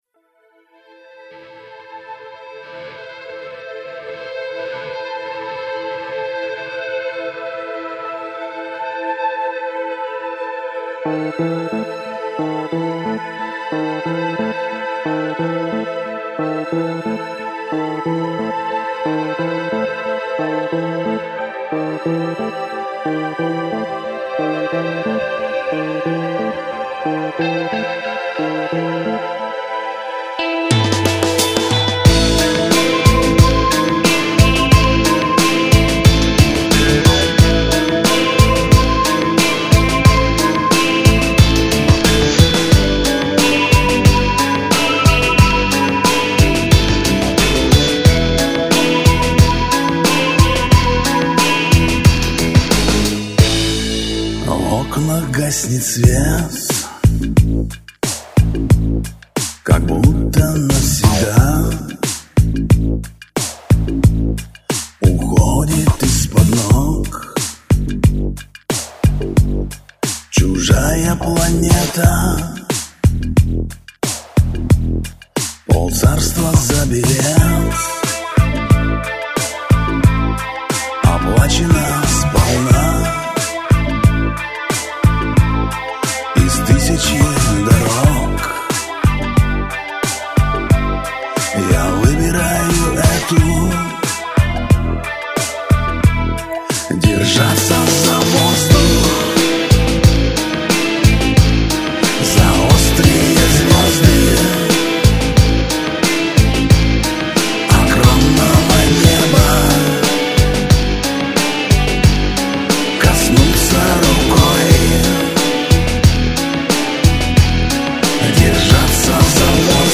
И слегка мешает восприятию легкий асинхрон на 2х голосах.